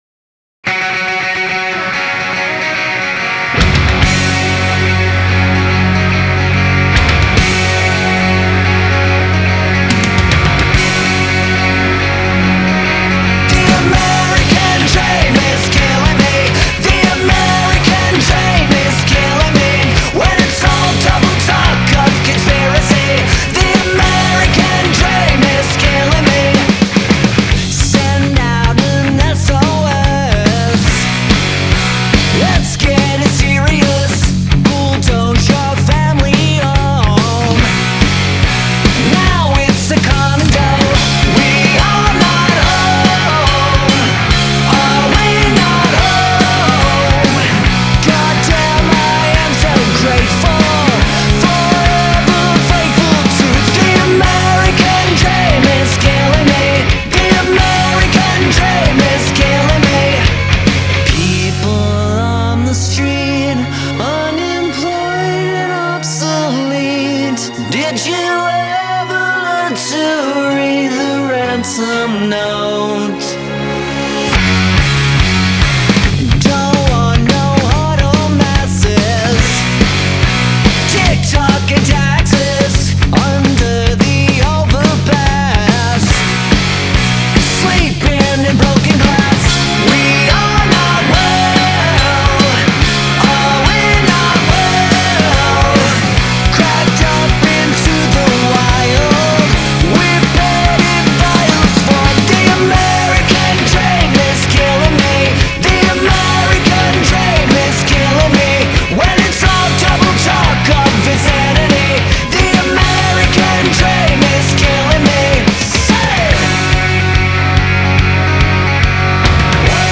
Punk Rock, Alternative Rock, Pop Punk